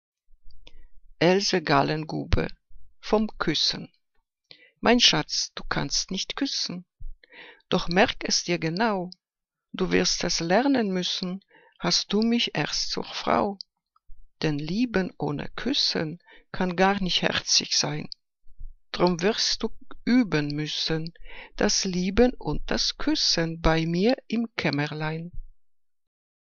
Ausgewählte Liebesgedichte